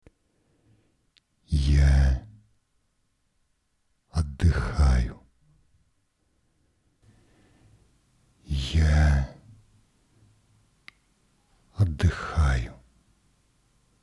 Для улучшения звука выбрал все самое стандартное - Shure SM58 и ставший практически стандартом для начинающих вещателей интерфейс Yamaha AG03 c отчаянно шумящим предусилителем D-Pre на борту.
В первой части прилагаемого файла можно слышать звучание чистого D-Pre со вкрученным почти на максимум гейном (девятое деление из десяти возможных) А во второй части звучит "динамит".
Шумит намного больше чистой Ямахи.